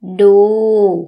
– duu